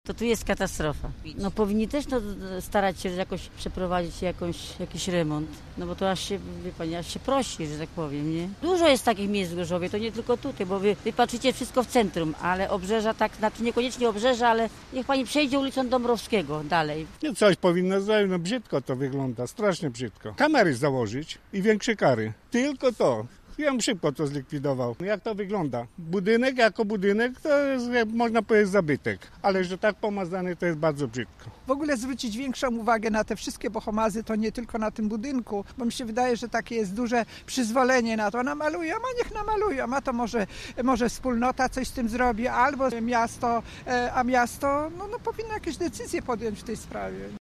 Malunki pseudo-graficiarzy przeszkadzają też mieszkańcom, z którymi rozmawialiśmy.